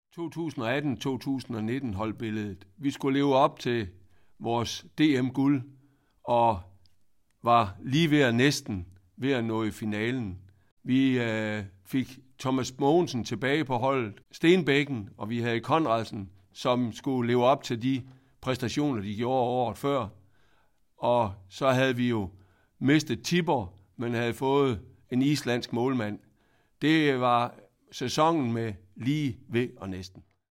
Klik på de enkelte fotos og hør Anders Dahl-Nielsens kommentarer om holdet/sæsonen